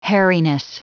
Prononciation du mot : hairiness
hairiness.wav